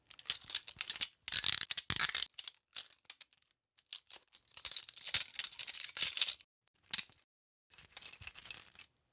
handcuffon.wav